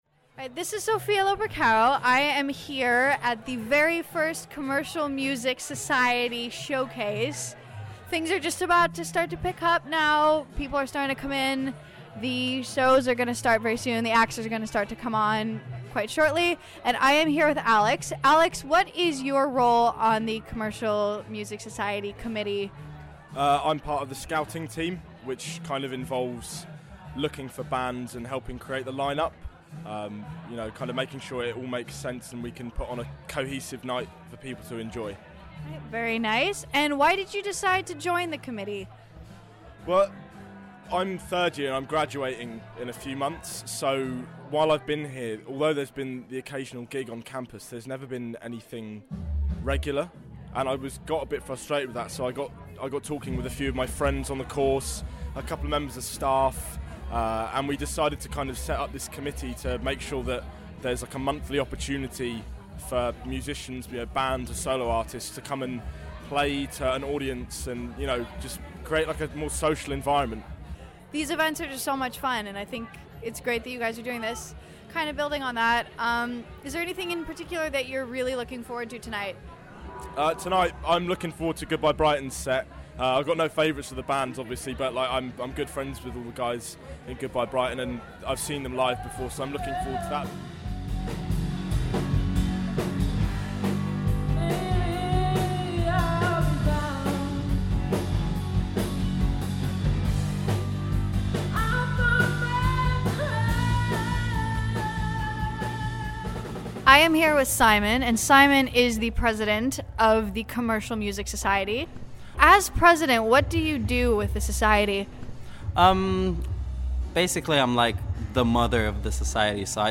The Commercial Music Society's first gig took place on Monday March 29th. We went along to not only support them but grab a few interviews.